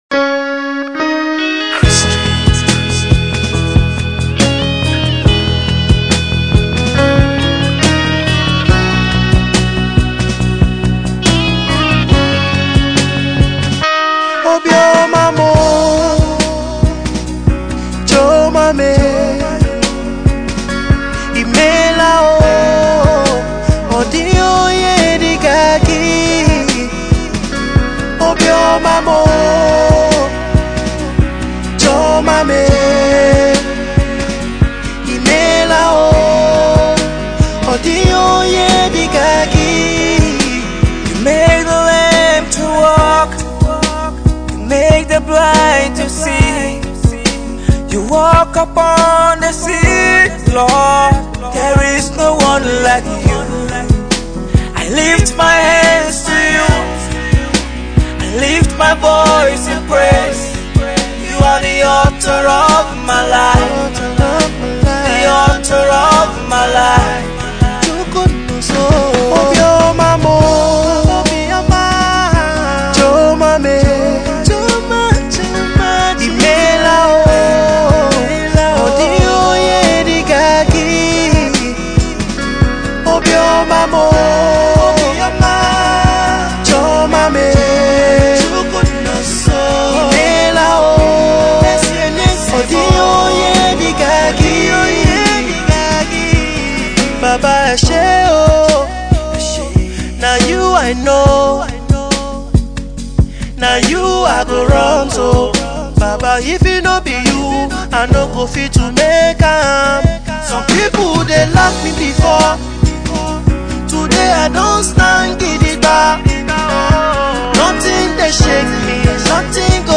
is a fast rising Nigeriain contemporary Gospel Artiste